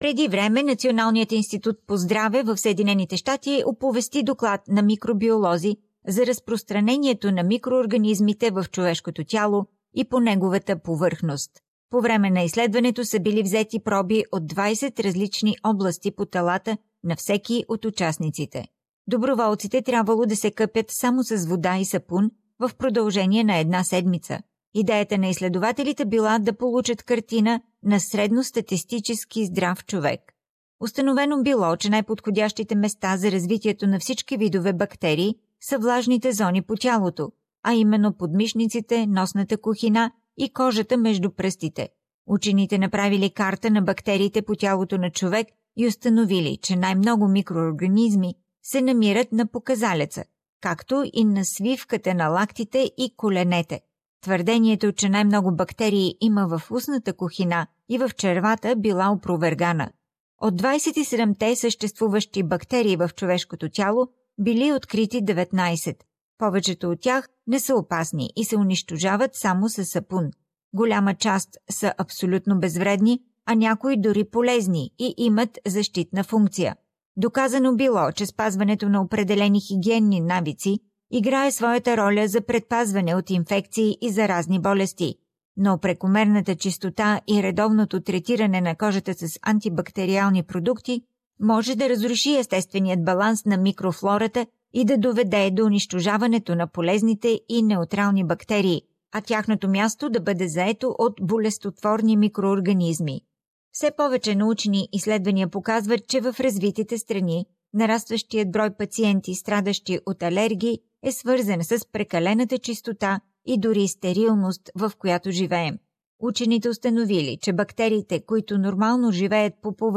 Our flashback interview, with which we mark the 40 th birthday of Bulgarian program on SBS Radio, is on that topic.